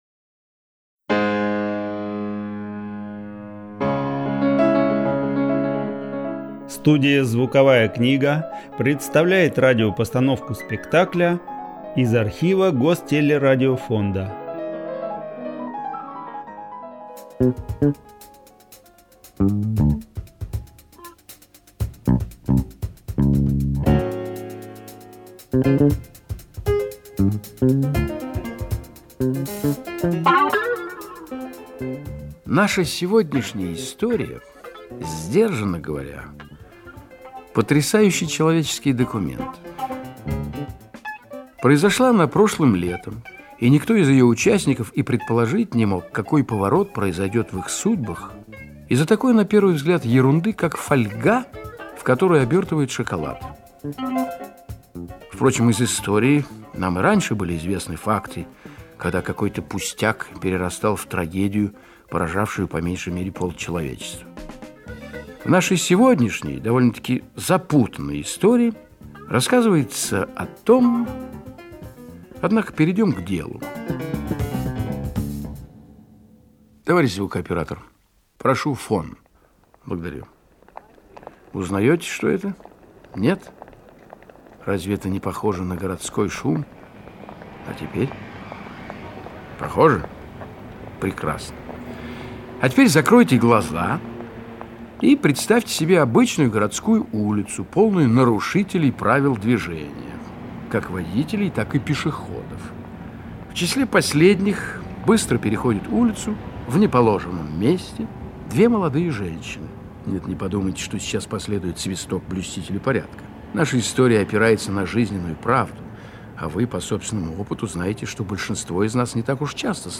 Лийвес "Фольга" ( аудиоспектакль )
Театр у микрофона
Рассказчик — Гафт Валентин
Женщина — Васильева Екатерина
Мужчина — Волков Николай